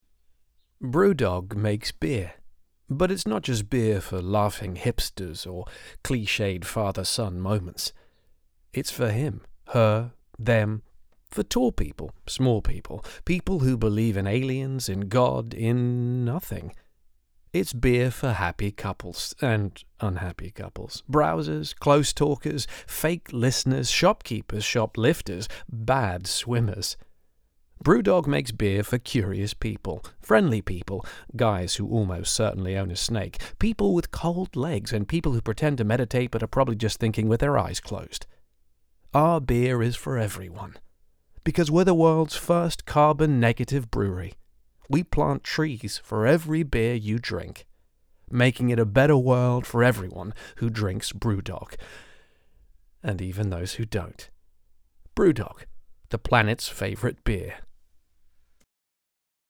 Canadian
Mid-Atlantic
Male
Characterful
Cool
Storytelling
BREWDOG COMMERCIAL